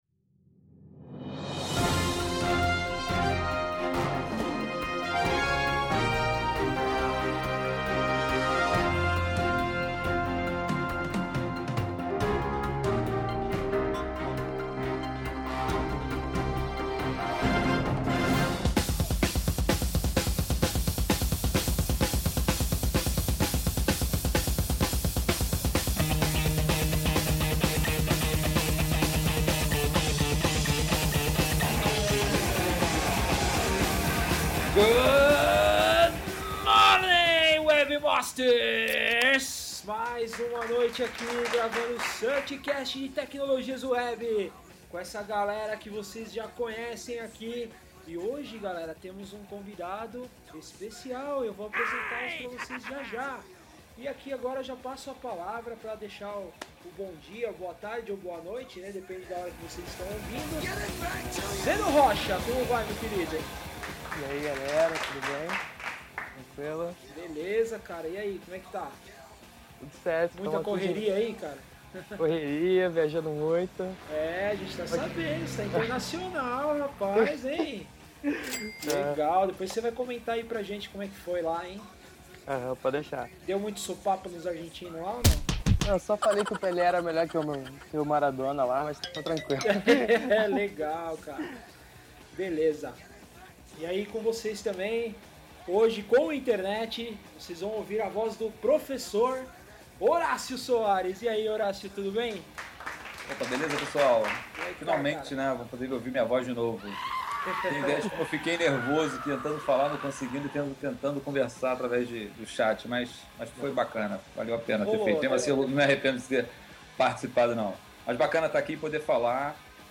Nossos convidados destrincharam todas as dúvidas e preocupações dos desenvolvedores com relação a design responsivo, comentando tudo o que há de novo no mercado e discutindo sobre as tendências futuras. Como não poderia faltar, mais uma vez o programa traz muito humor e comentários vorazes de mestres da área.